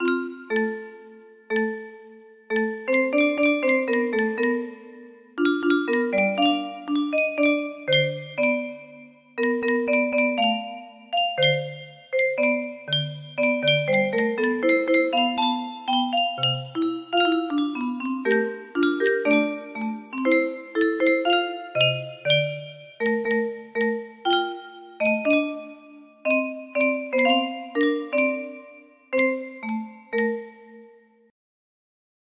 Albert Methfessel - Stadt Hamburg an der Elbe Auen music box melody